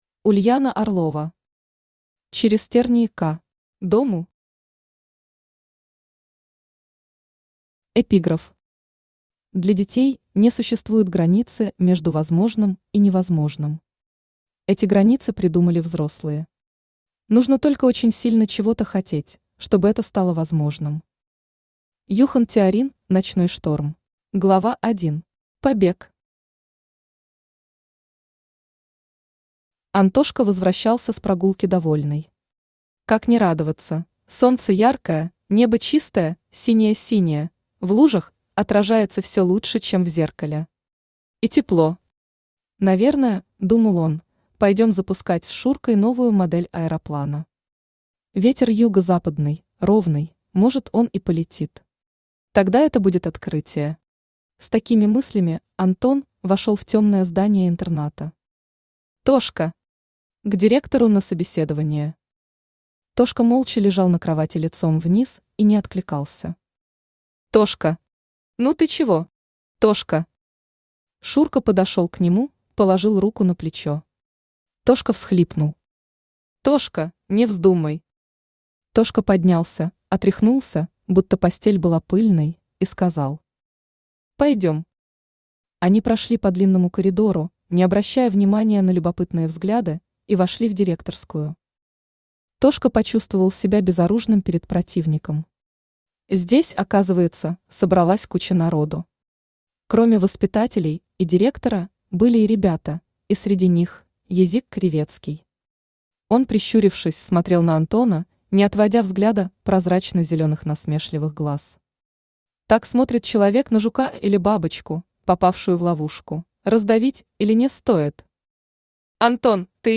Аудиокнига Через тернии к… дому | Библиотека аудиокниг
Aудиокнига Через тернии к… дому Автор Ульяна Владимировна Орлова Читает аудиокнигу Авточтец ЛитРес.